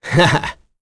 Roi-Vox-Laugh1.wav